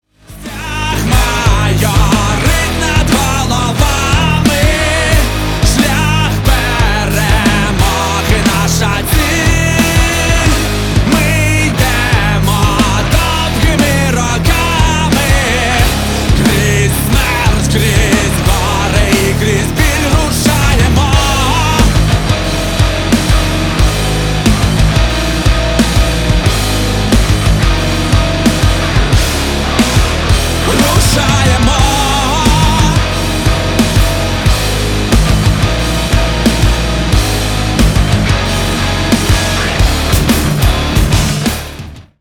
• Качество: 320, Stereo
жесткие
мощные
nu metal
украинский рок
злые